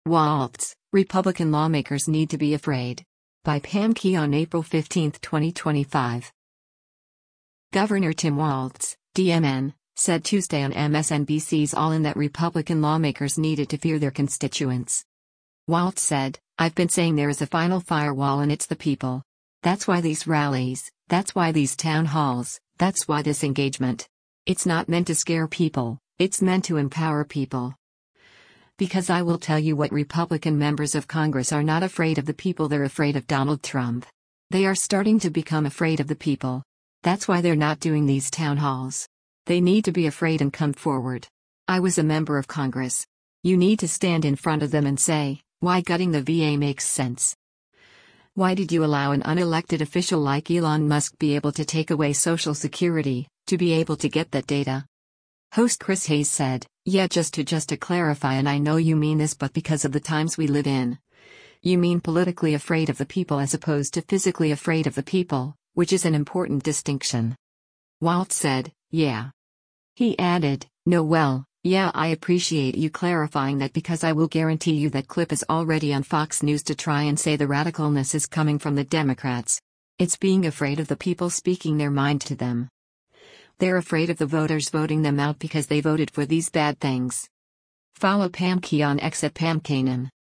Governor Tim Walz (D-MN) said Tuesday on MSNBC’s “All In” that Republican lawmakers needed to fear their constituents.